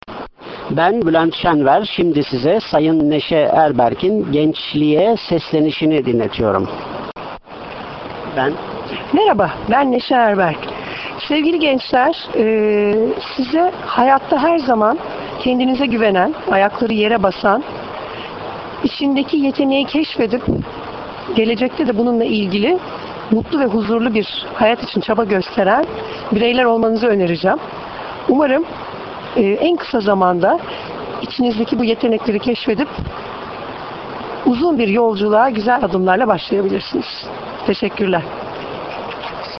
NEŞE ERBERK Gençliğe Seslenişini kendi sesinden dinlemek isterseniz, bilgisayarınızın sesini açıp,